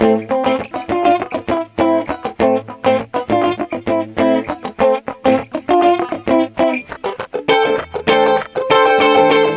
Sons et loops gratuits de guitares rythmiques 100bpm
Guitare rythmique 25